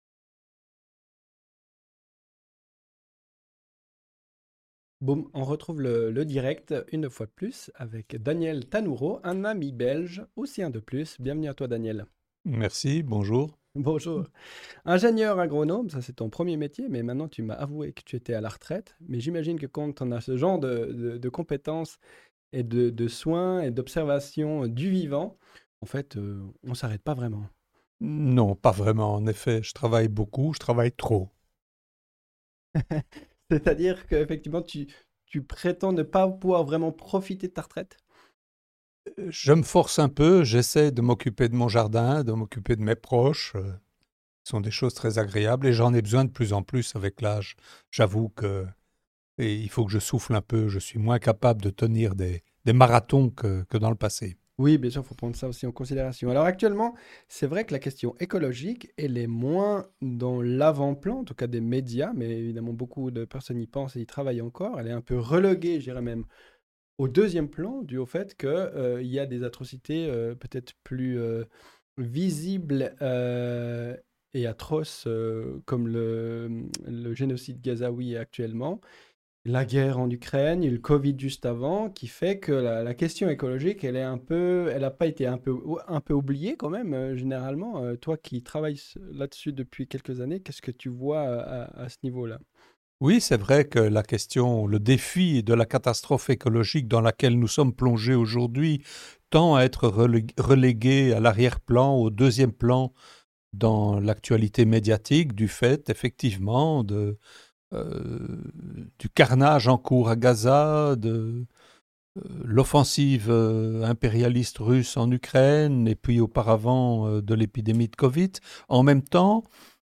Nous proposons des conversations [en direct] sans filtre, longs formats, libres, puissantes, profondes, authentiques, nourrissantes et porteuses de sens en libre accès sur Internet.